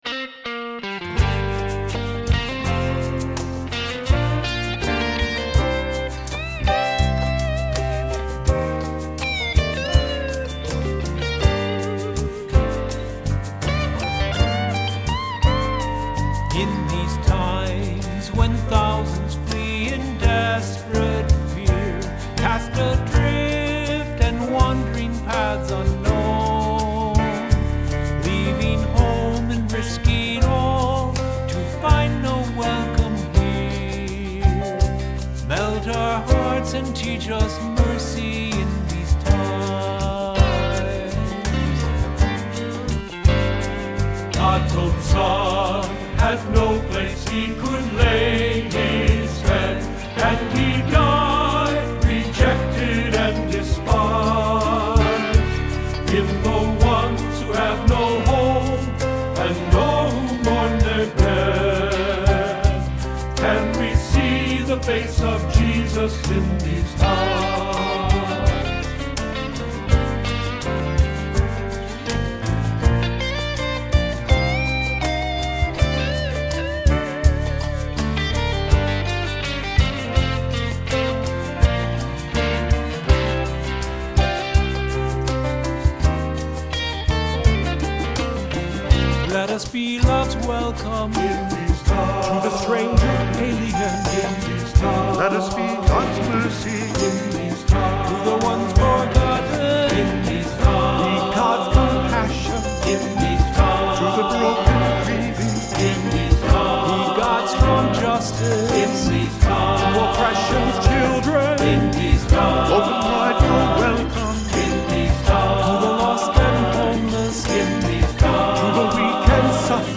Accompaniment:      Keyboard, C Instrument I;C Instrument II
Music Category:      Christian
For cantor or soloist.